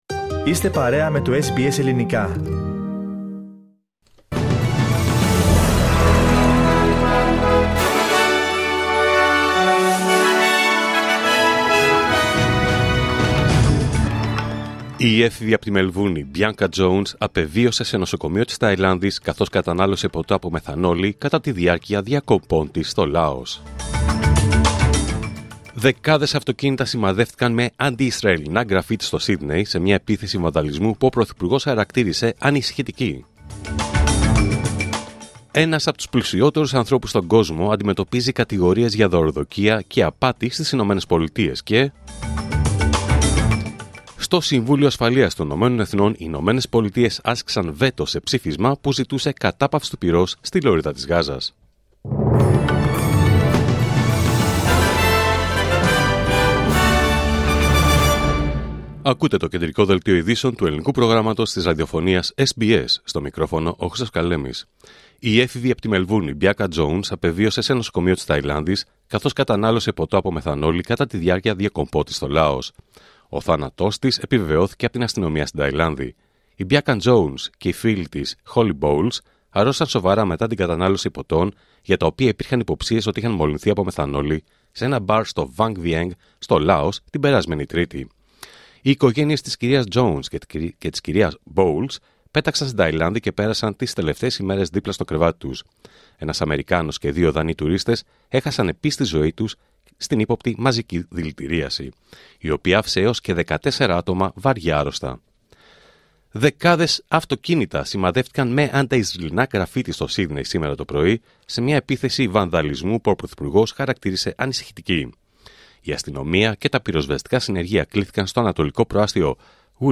Δελτίο Ειδήσεων Πέμπτη 21 Νοέμβριου 2024